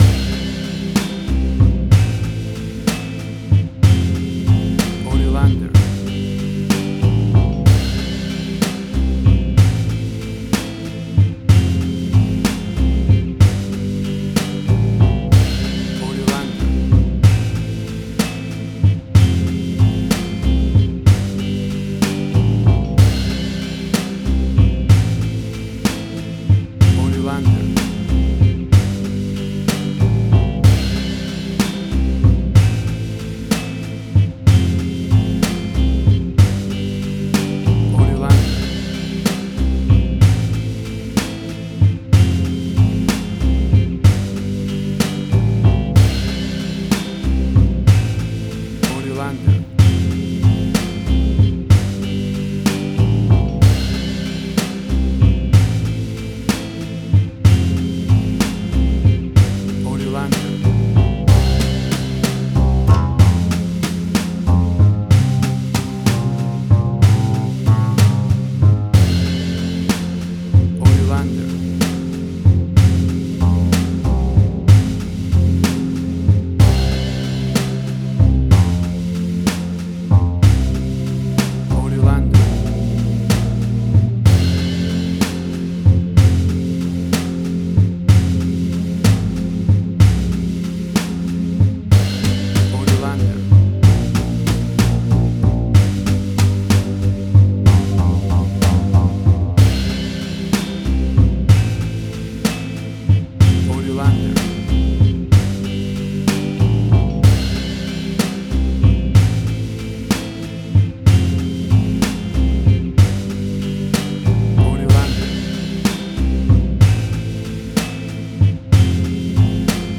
A cool 1950s piece of retro americana!
This will take you right back to the fifties in style!
Tempo (BPM): 63